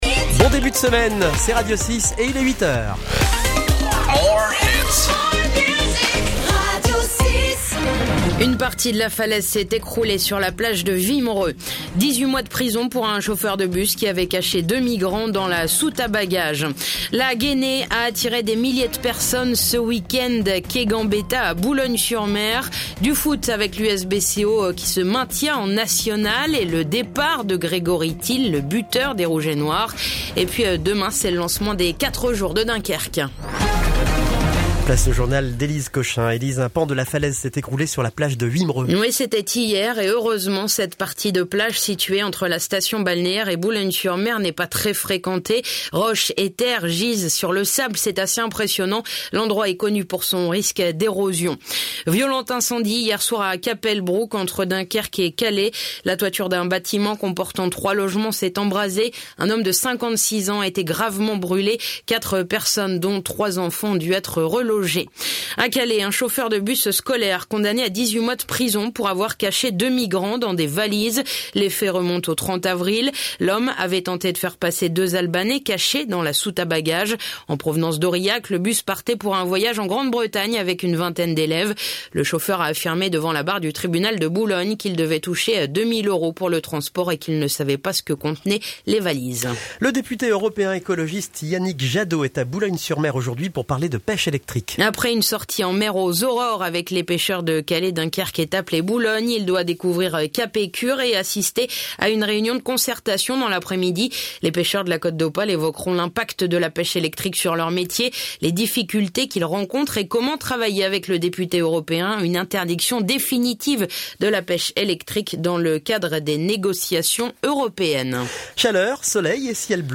L'essentiel de l'actu de la Côte d'Opale et les titres de l'actu nationale et internationale dans un journal de 6 minutes ! (édition de 8h)